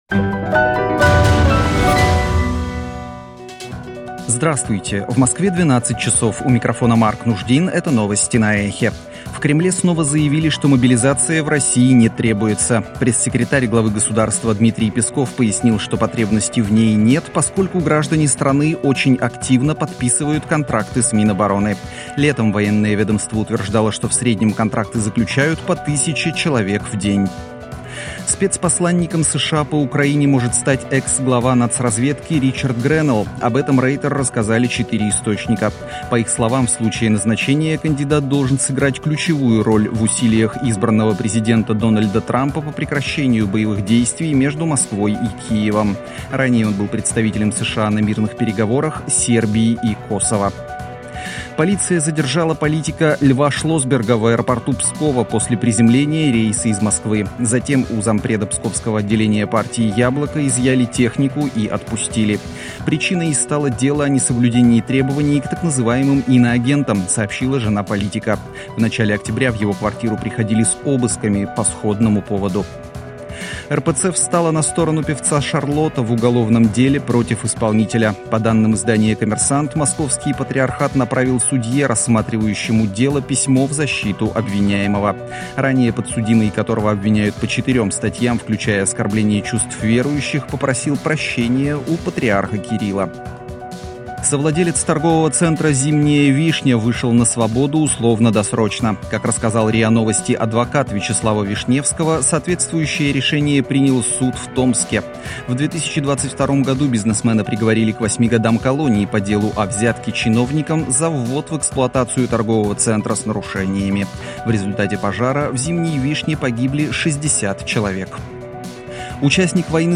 Свежий выпуск новостей